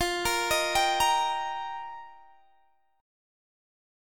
Listen to F7sus2sus4 strummed